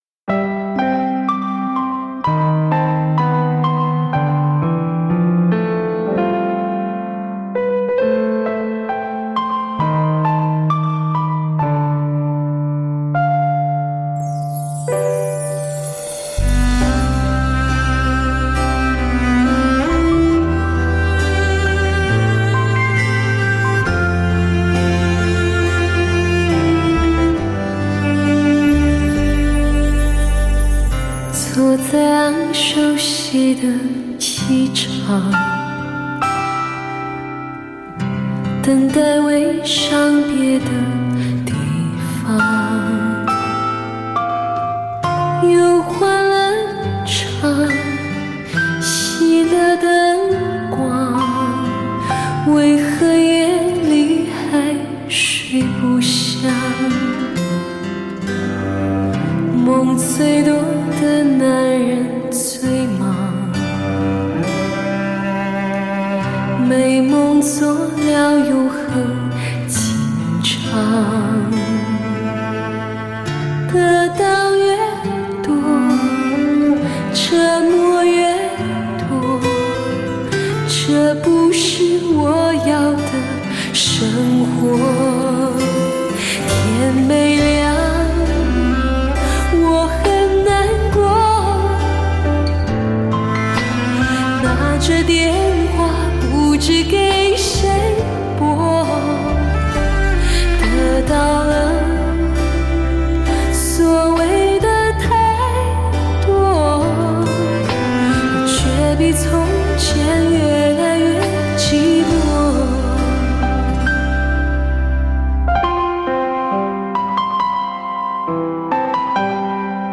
华丽惊艳，好歌精彩，发烧妙品，试音经典。
形式，结像清晰，低、中、高频延伸自然，对设备定位及还原极具考验。
的磁性嗓音，最适合舒缓压力放松心情的音乐作品，最具典藏纪念价值不可不听得发烧极品。